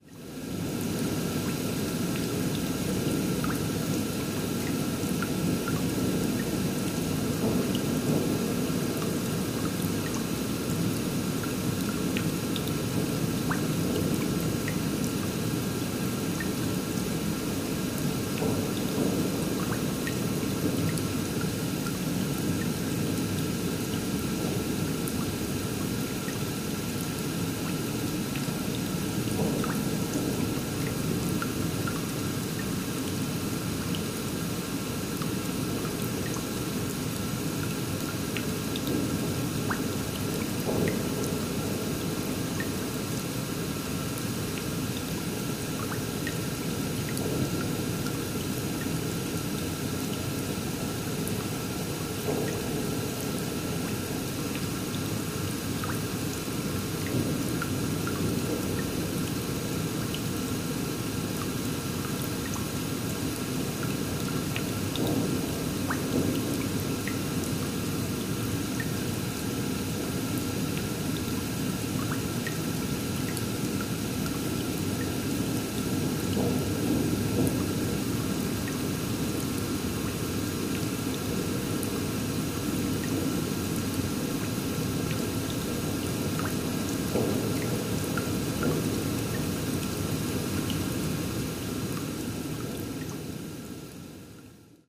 Basement Ambiance